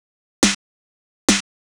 Techno / Drum / SNARE026_TEKNO_140_X_SC2.wav